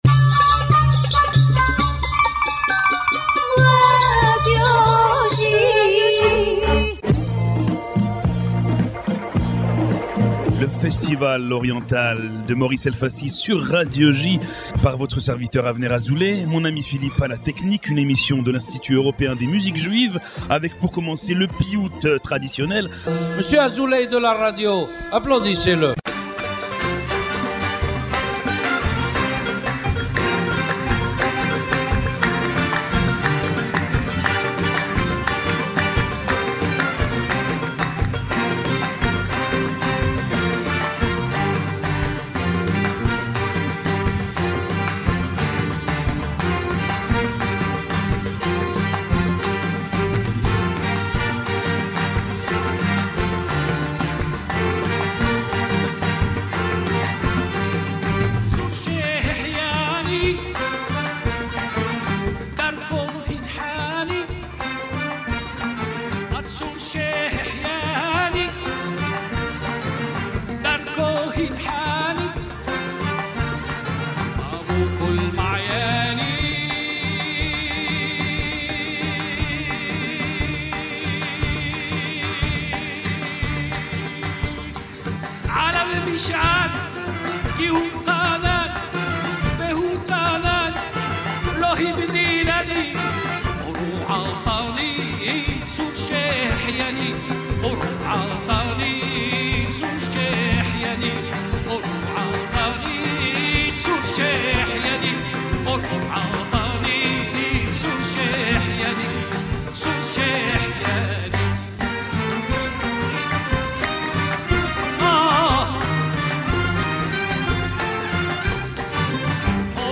Diffusé chaque lundi sur les ondes de Radio J (94.8 FM), Le festival oriental est une émission de l’Institut Européen des Musiques Juives entièrement dédiée à la musique orientale.